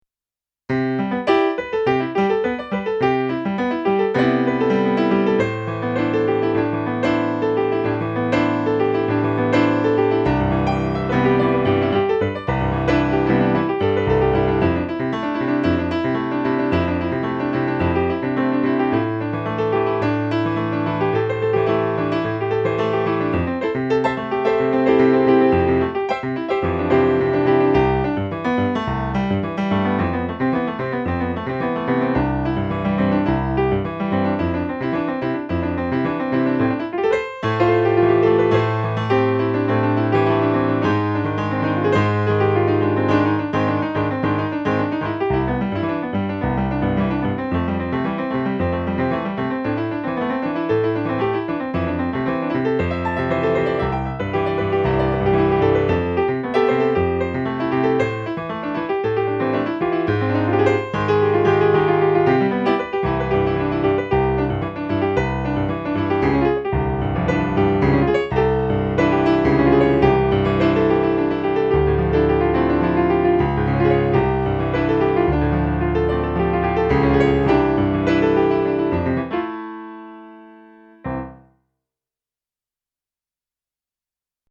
These improvisations are just musical ideas and expressions all created in the moment, a stream of consciousness. The keyboard used is a controller so the musical response quality is limited to the nature of the animal.